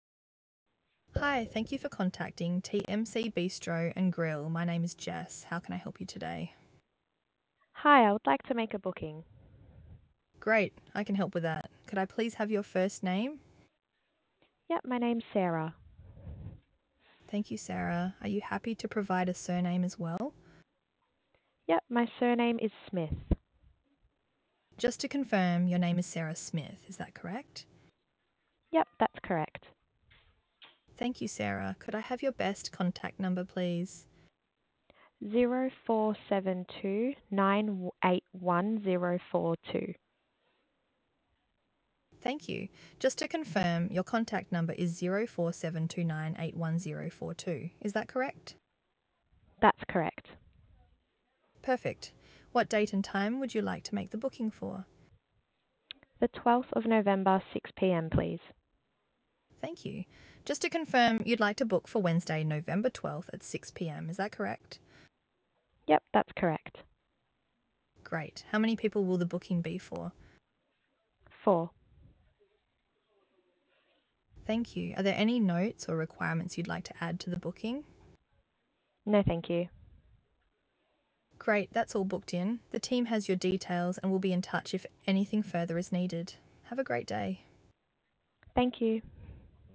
Take a moment to listen to our AI agent in action.